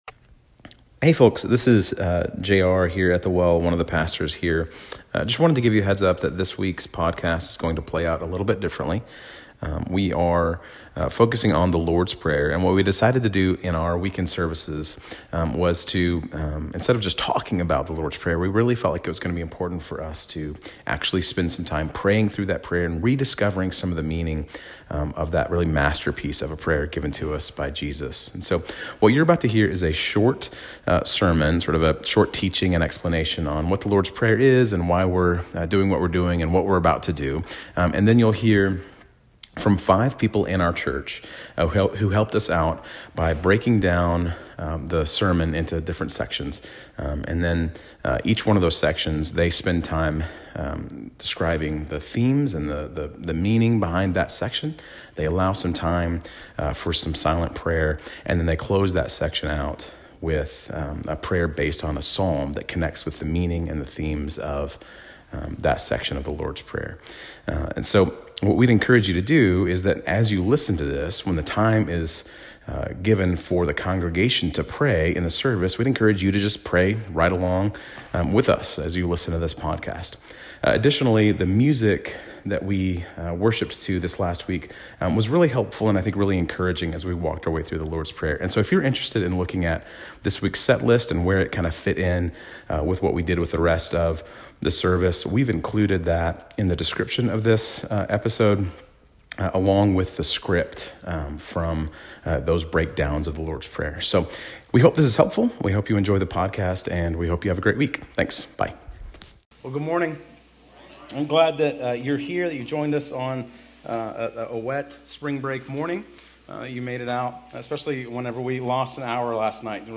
As we are walking through the sermon on the mount, this week’s passage was the portion on “The Lord’s Prayer”. As a church we took time to pray and worship together through each portion of the prayer, each prayer led by members of our church.
Service Type: Prayer Service